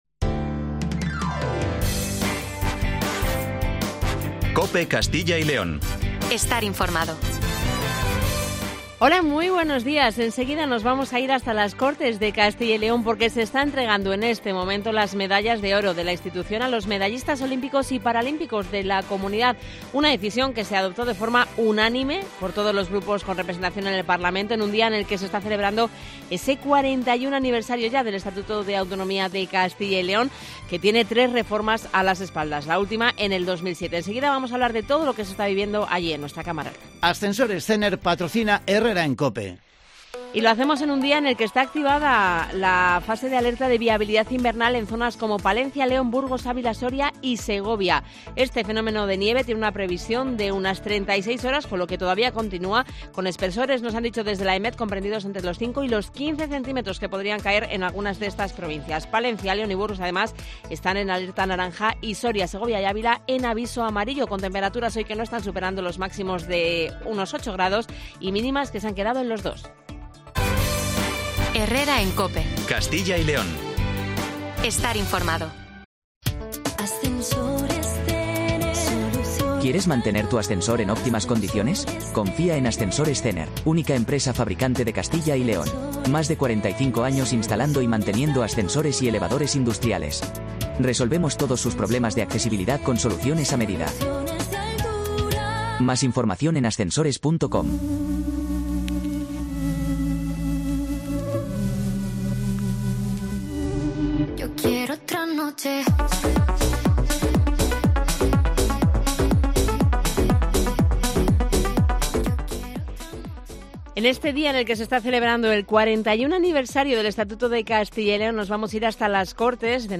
Seguimos en directo el acto Conmemorativo del 41 Aniversario del Estatuto de Autonomía de Castilla y León y entrega de la Medalla de las Cortes de Castilla y León.